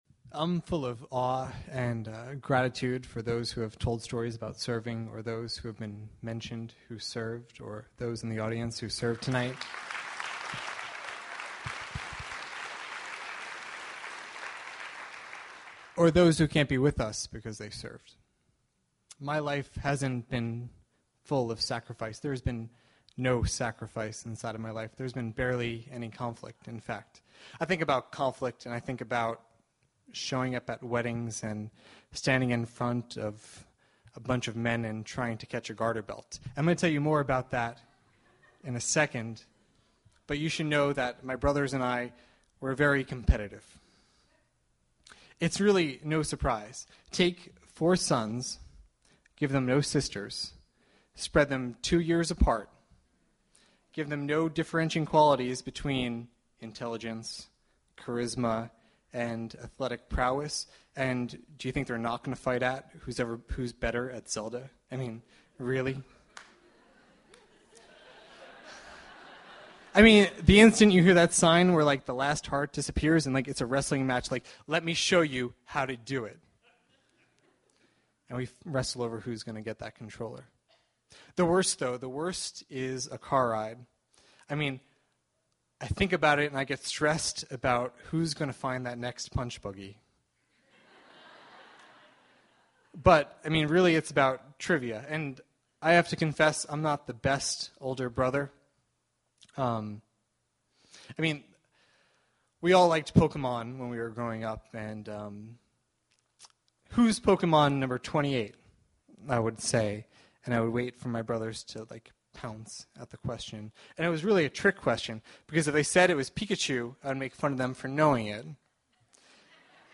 Stories of skirmishes, battles, victories and defeats Seven storytellers get seven minutes each to tell true personal tales of fights waged, won and lost. Music from “2econd N8ture”.